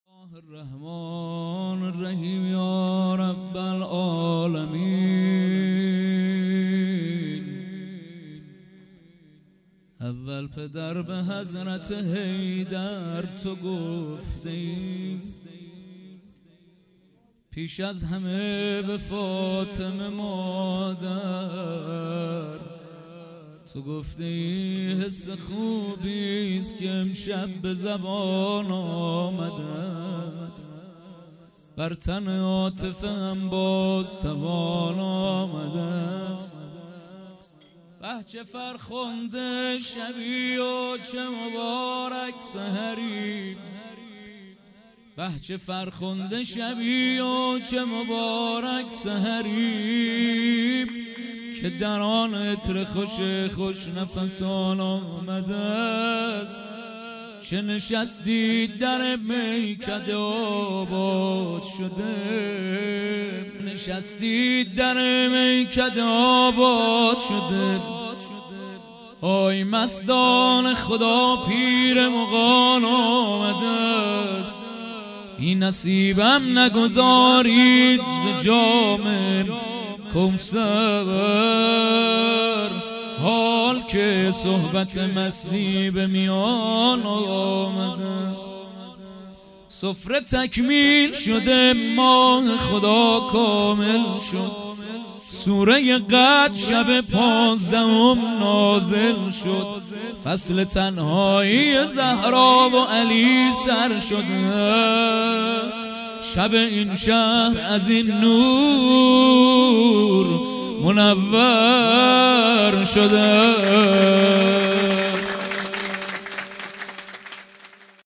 خیمه گاه - هیئت انصارالمهدی(عج) درچه - مدح | اول پدر به حضرت حیدر تو گفته ای